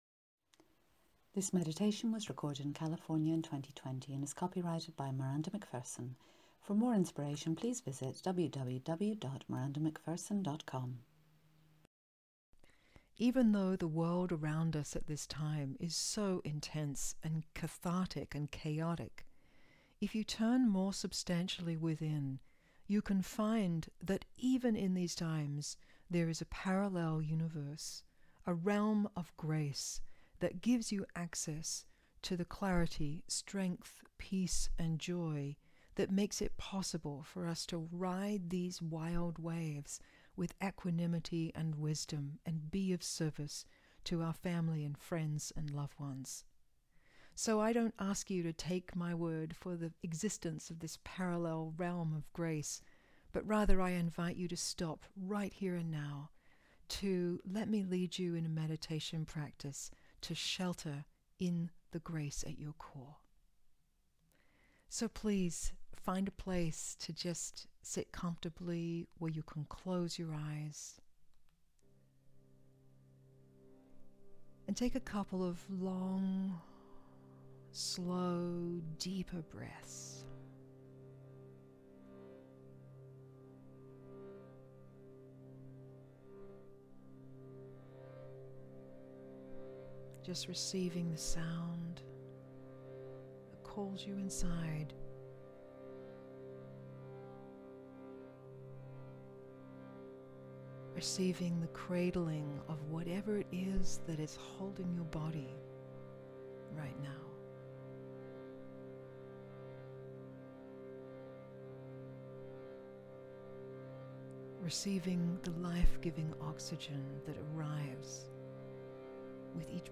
FREE DOWNLOADABLE MEDITATION
Sheltering-in-Grace-Meditation.mp3